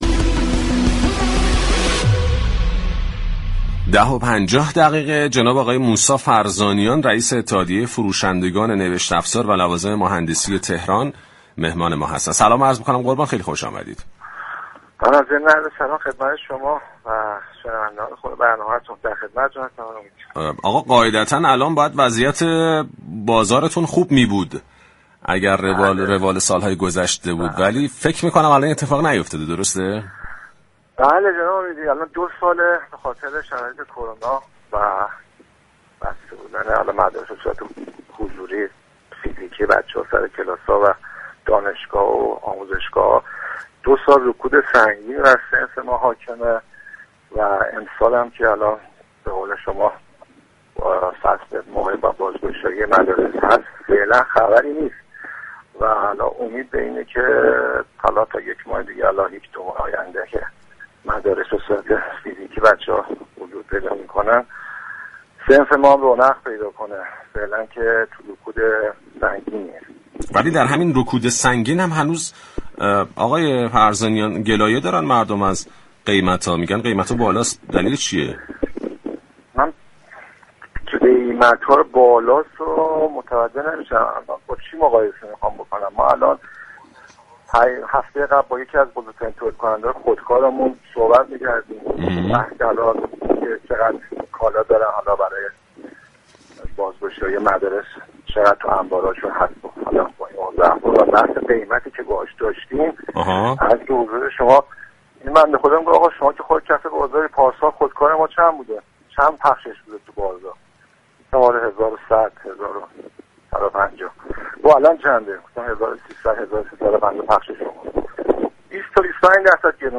وی در پایان این گفتگوی رادیویی خاطرنشان كرد: اگرچه این ممنوعیت واردات به صورت مقطعی صورت گرفته اما باید تمهیداتی برای تامین این اقلام نوشت افزاری اندیشیده شود.